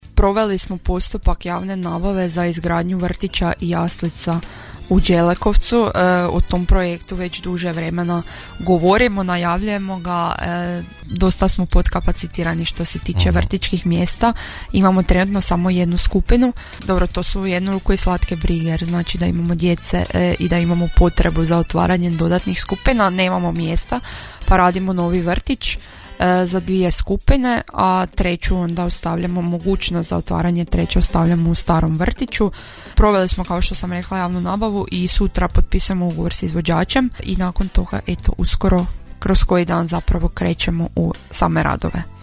Gošća emisije „Susjedne općine” Podravskog radija bila je načelnica općine Đelekovec Lara Samošćanec
Gošća u studiju Podravskog radija najmlađa načelnica u Hrvatskoj Lara Samošćanec